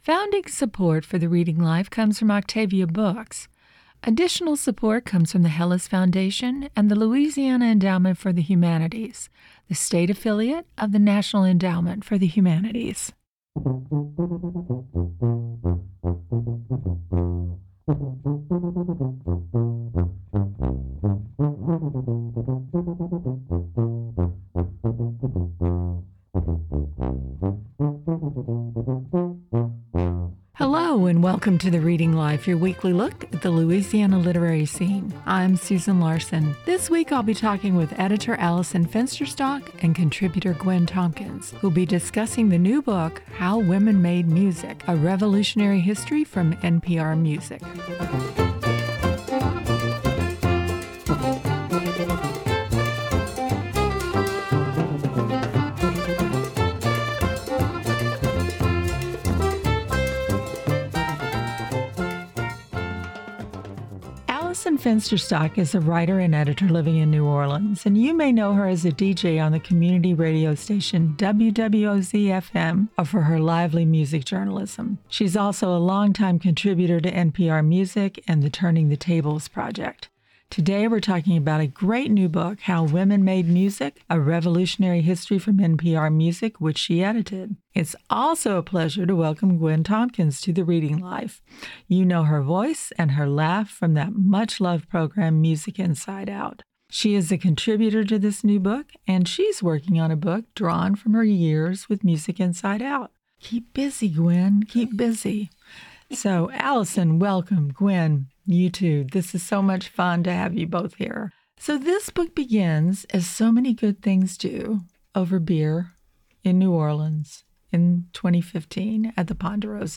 Hear celebrated and up-and-coming authors read excerpts from new books and discuss their work